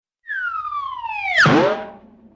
slide whistle fall and boomp Meme Sound Effect
slide whistle fall and boomp.mp3